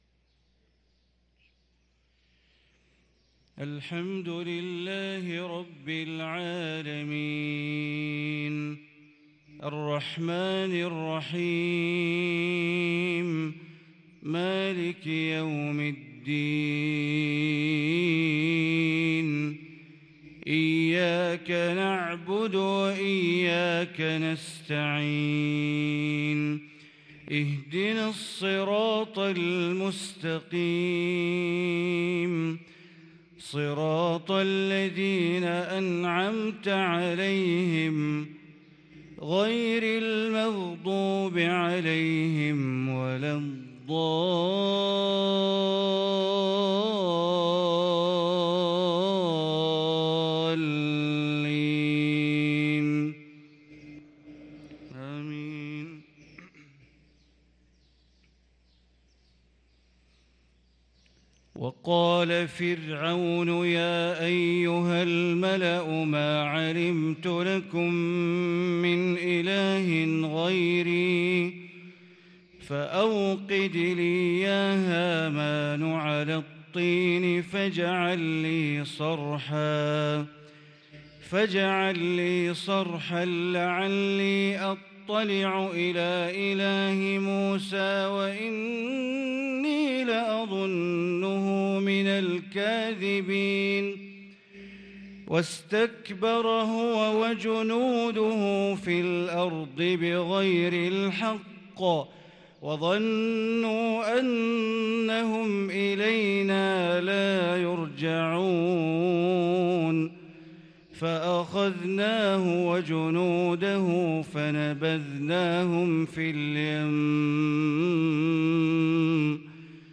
صلاة الفجر للقارئ بندر بليلة 10 جمادي الآخر 1444 هـ